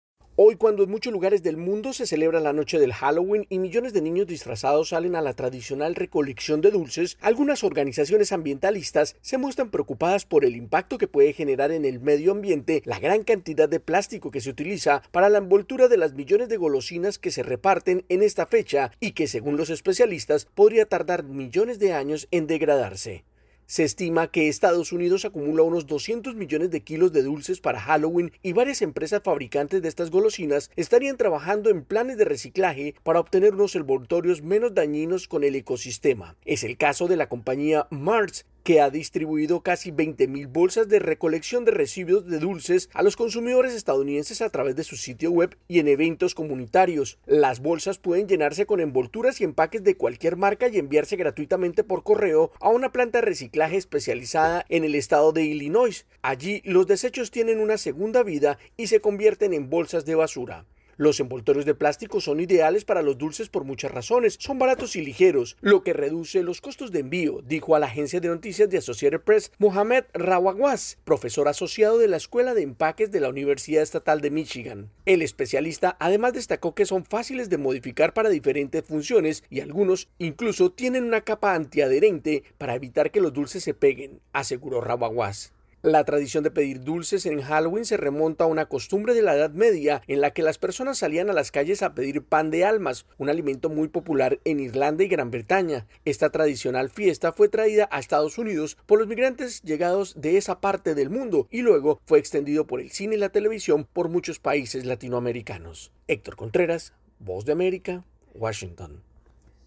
AudioNoticias
desde la Voz de América en Washington DC.